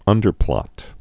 (ŭndər-plŏt)